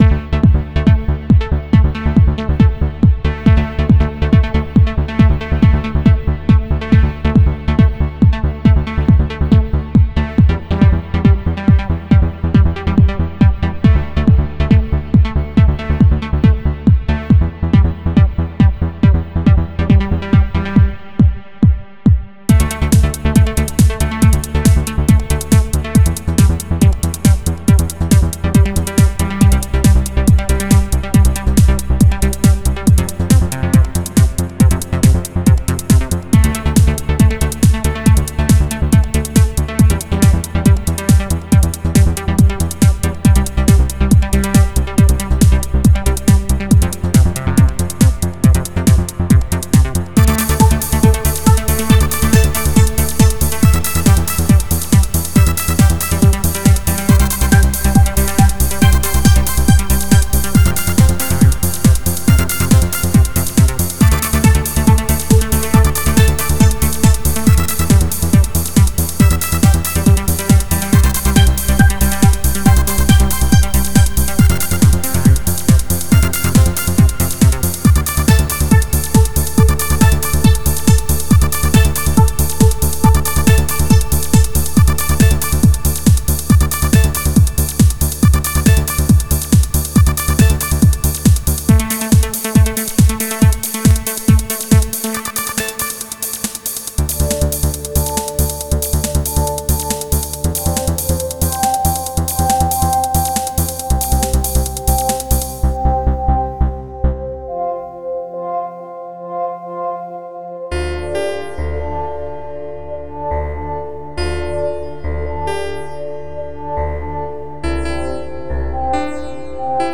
Трек состоит из нескольких тем и стилей,объединенный в общем-то одной мелодией...